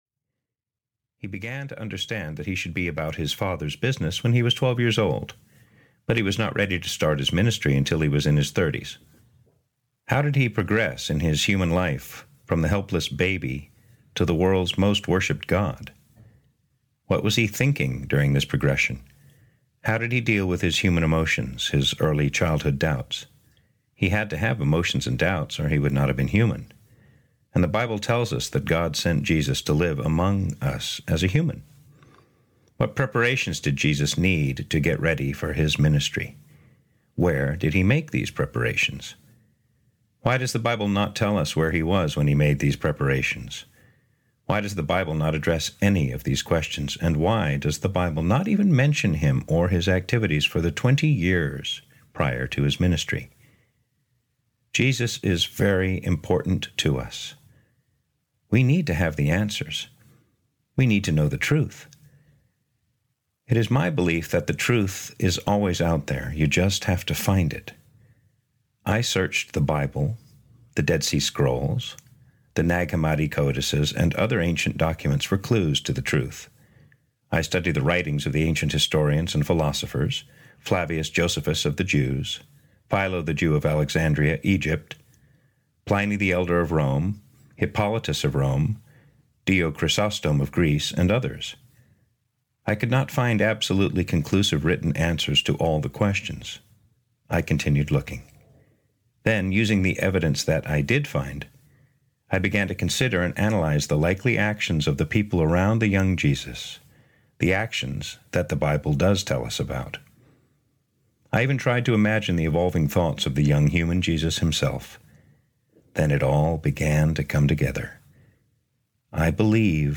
Audio knihaJesus: The Missing Years (EN)
Ukázka z knihy
• InterpretAlex Hyde-White